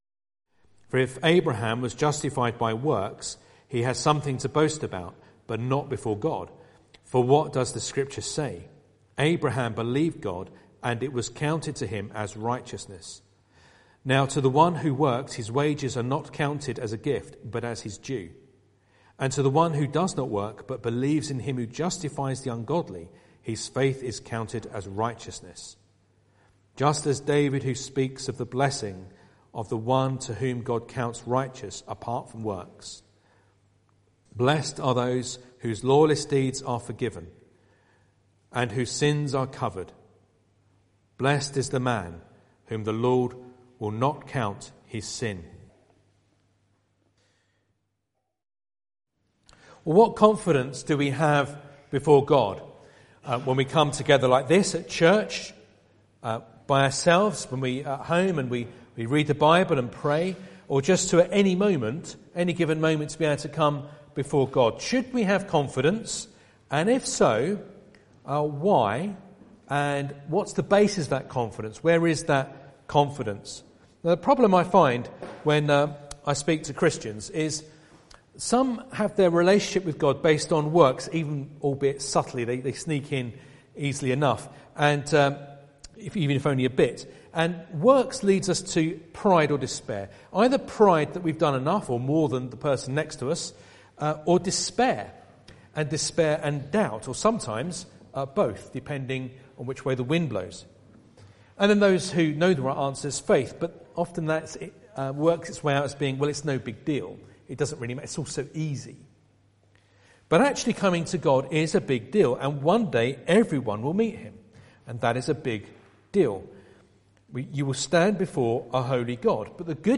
Romans 4:1-8 Service Type: Sunday Evening Bible Text